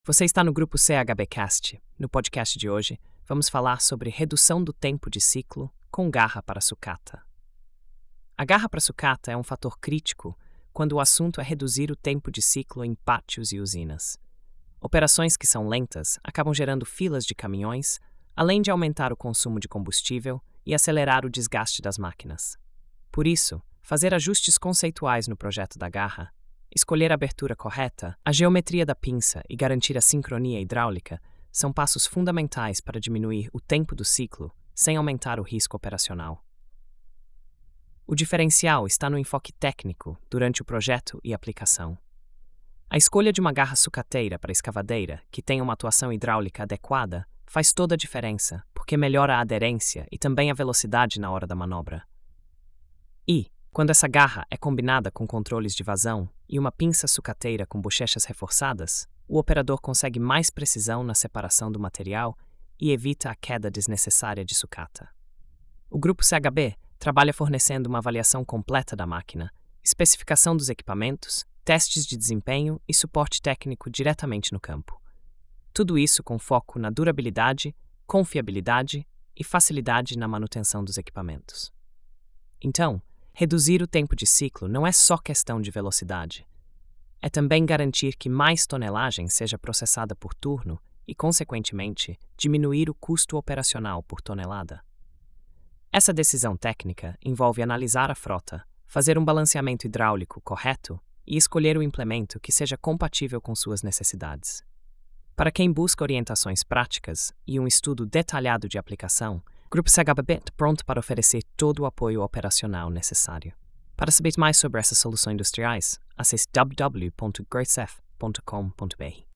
Narração automática por IA